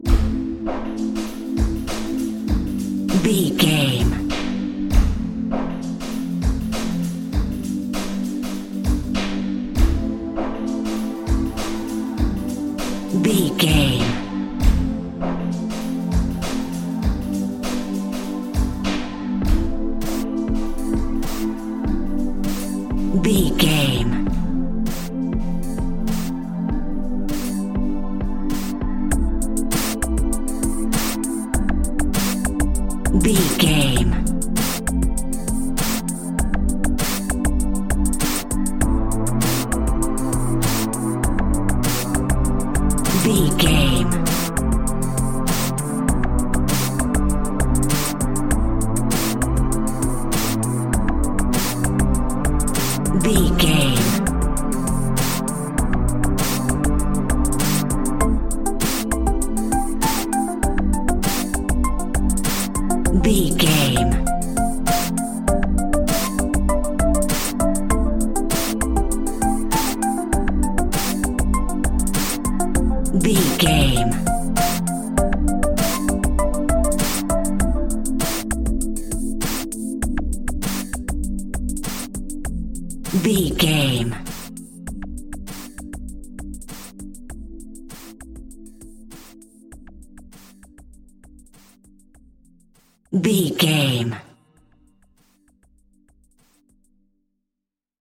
Aeolian/Minor
electronic
synths
drone
glitch
synth lead
synth bass